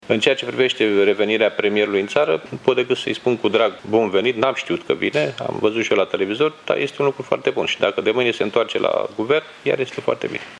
Coordonatorul politic al PSD, Liviu Dragnea, a declarat, astăzi, la Reghin, unde a participat la o întâlnire cu membrii organizației din Mureș, că venirea premierului în țară este un lucru pozitiv, la fel ca și întoarcerea sa de mâine la cârma Guvernului.